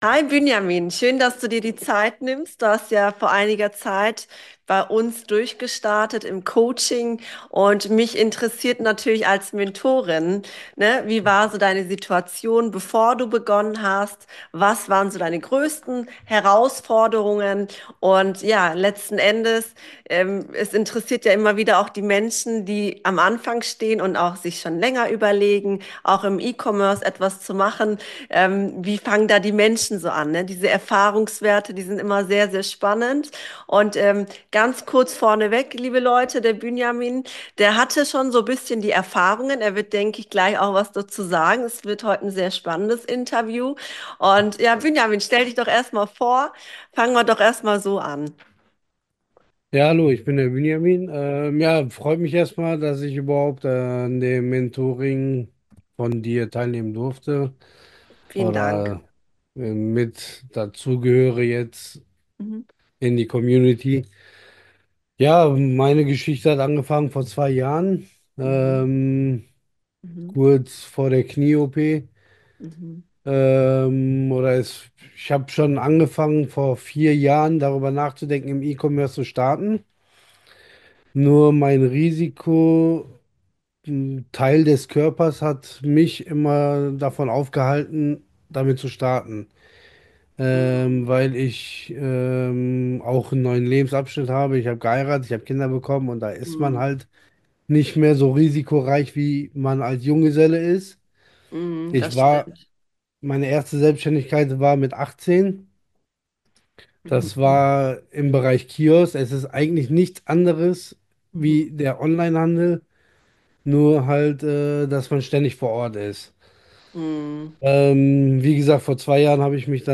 # 69 Kundeninterview: Vom Eigenmarken-Flop zum Reselling-Boom – Sein Amazon Gamechanger!